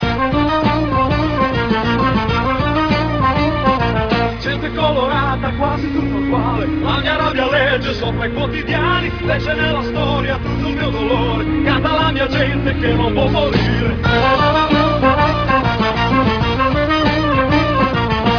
voce
sample in real player inferiore a 30 secondi in qualità mono